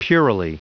Prononciation du mot puerilely en anglais (fichier audio)
Prononciation du mot : puerilely